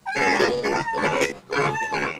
Pig_Peaceidle_00.wav